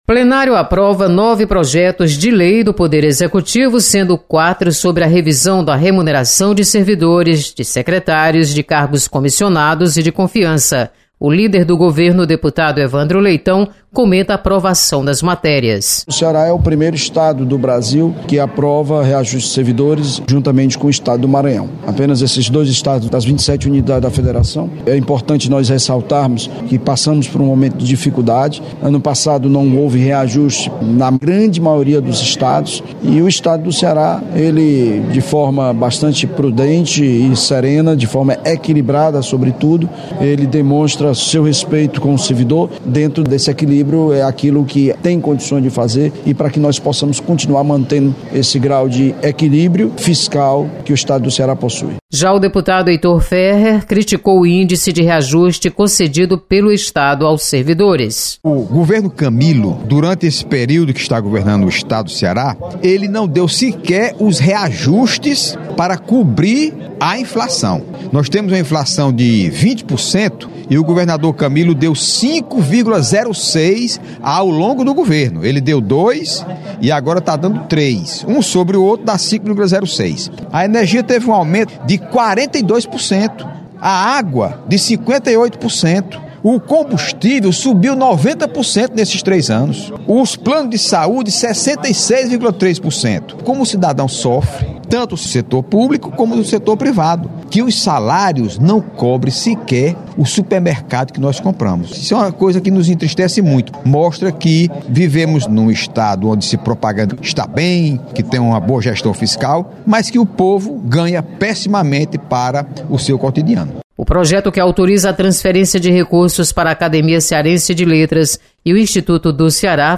Você está aqui: Início Comunicação Rádio FM Assembleia Notícias Reajuste